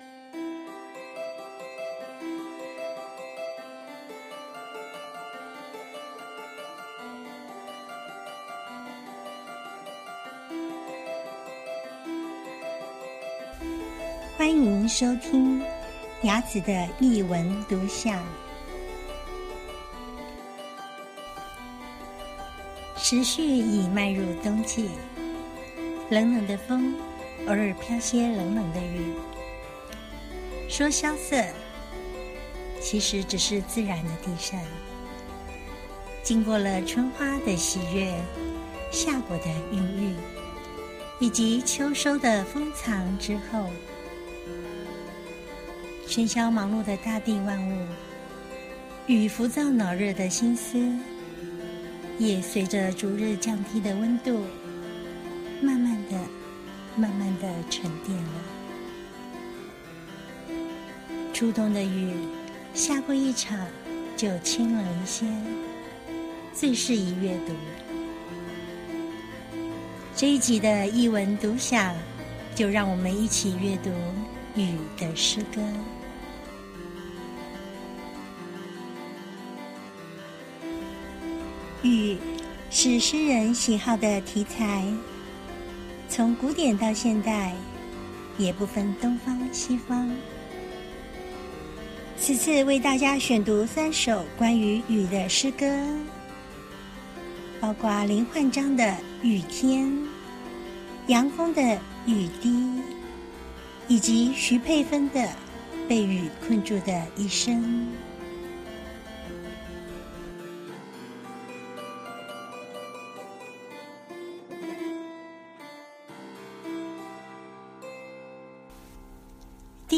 音樂演奏